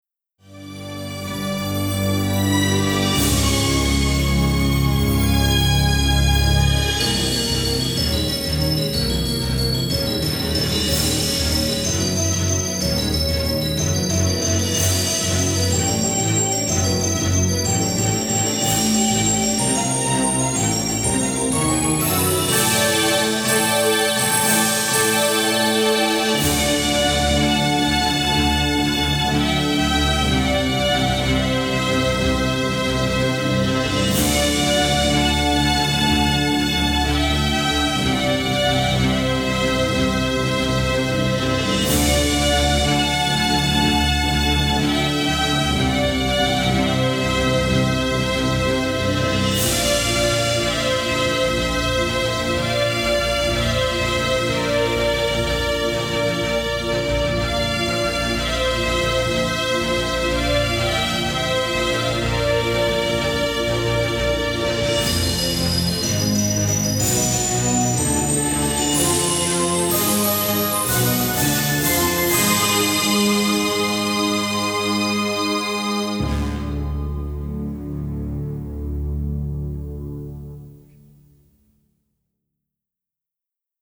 sci-fi soundtrack